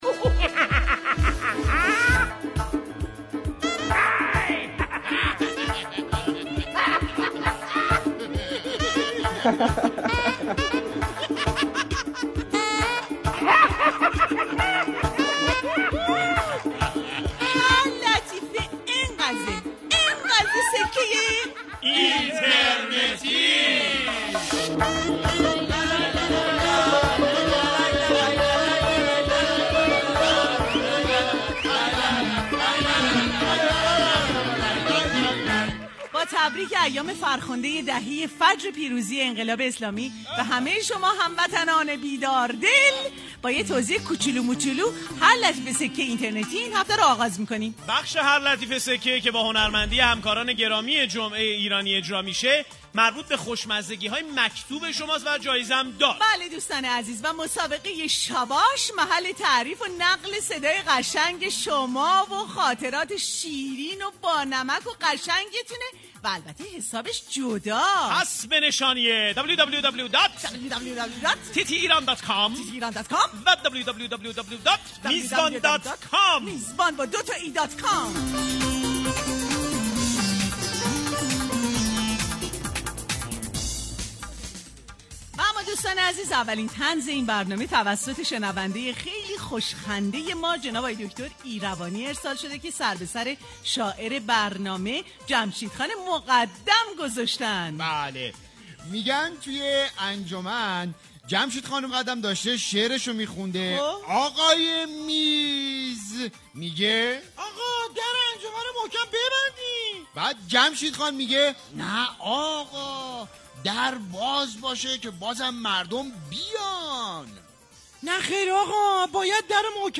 • برنامه طنز جمعه ایرانی هر جمعه ساعت 9 تا 11:30 از رادیو ایران